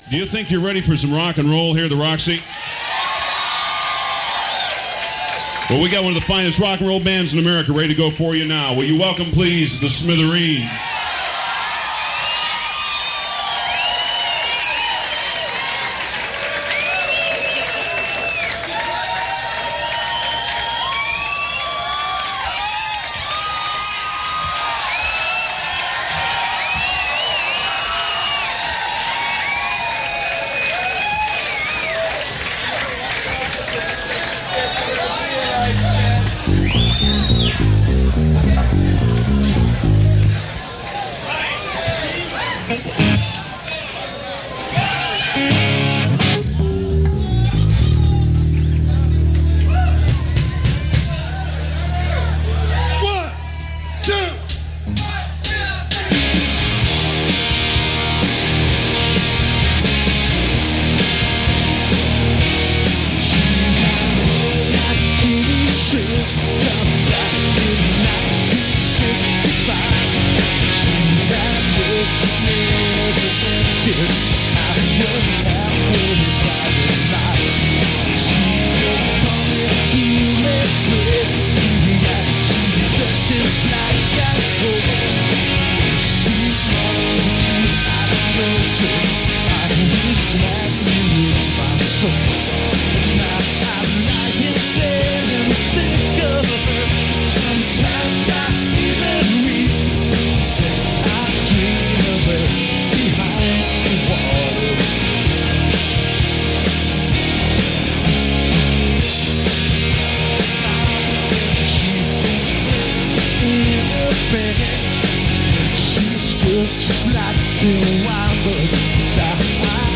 In Concert From The Roxy – West Hollywood